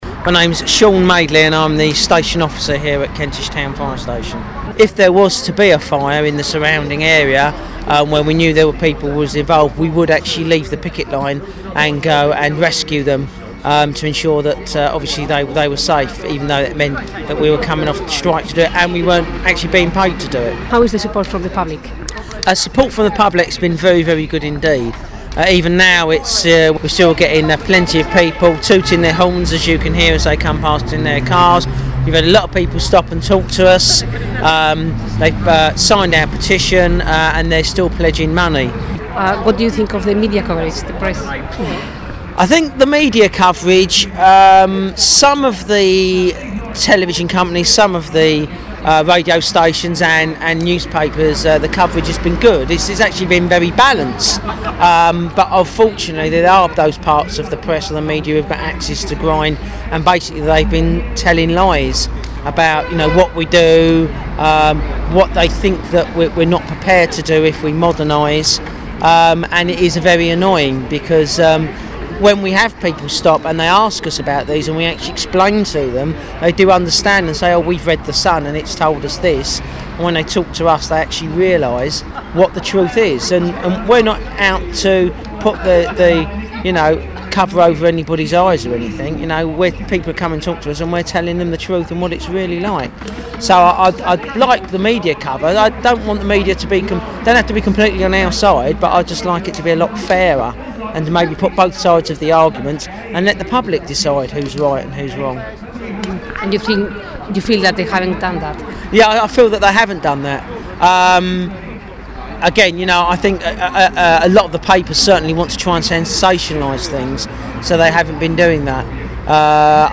3rd interview